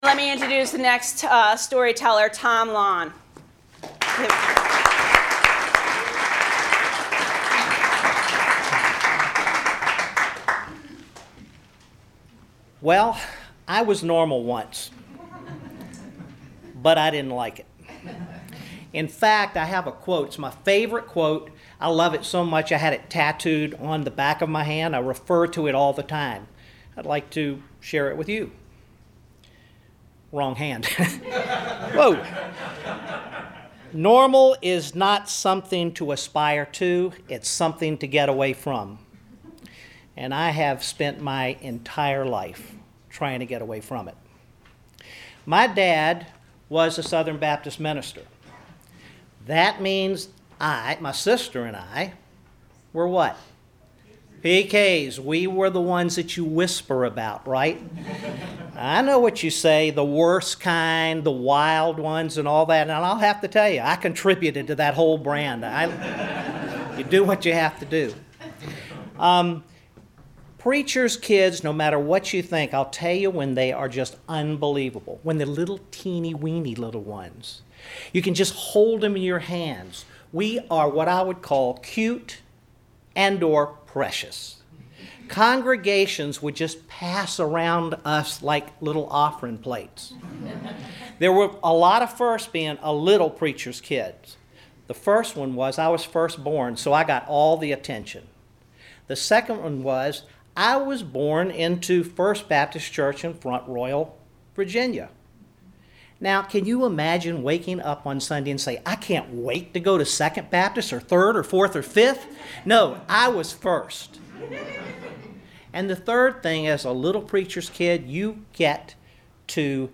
I had the opportunity to be one of nine storytellers who crafted and shared our stories to a live and lively audience at a wonderful theater in Richmond the other night.